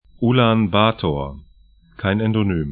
Ulan-Bator 'u:lan'ba:to:ɐ